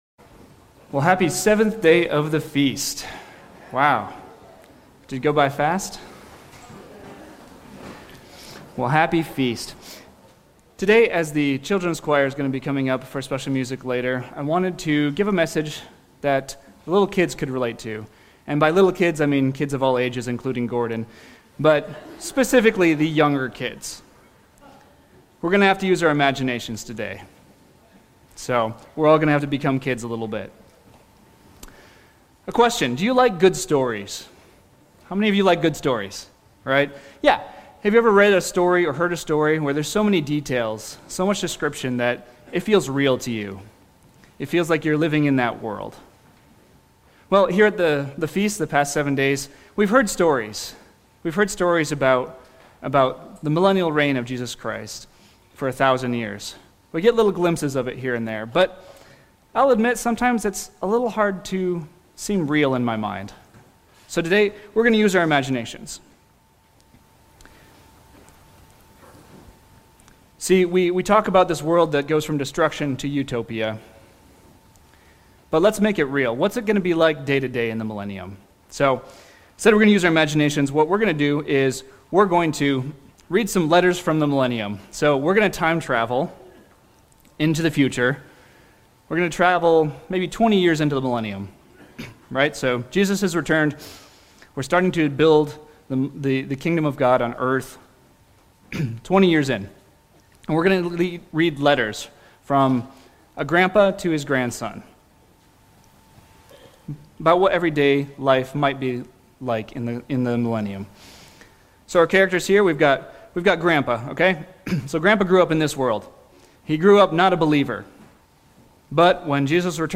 Sermons
Given in Walnut Creek, Ohio